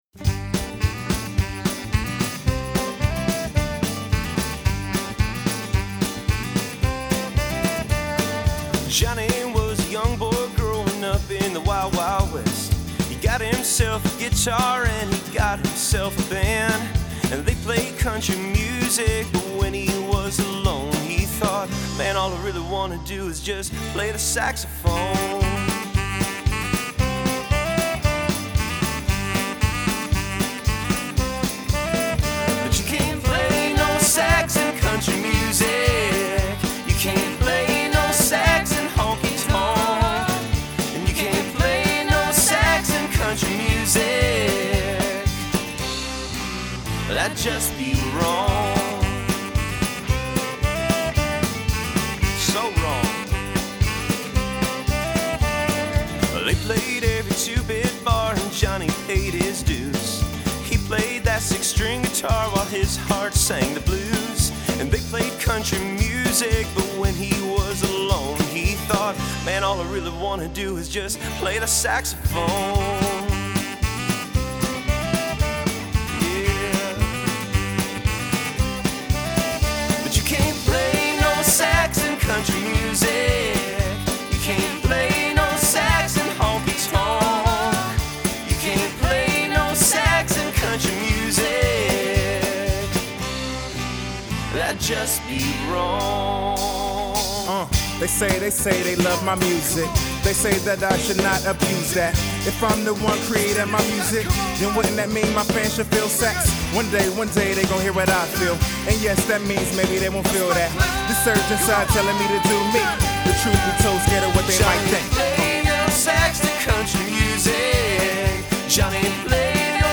Out of that jam session came the idea for a country song featuring a saxophone.
The whole song probably jumps at least 20 BPM from start to finish, but hey, who’s counting?!
We wanted to stick to a completely analog experience.
saxophone
vocals, acoustic guitar, electric guitars
backing vocals, keyboards, tamborine
bass guitar
rap, drums
no-sax-in-country-music.mp3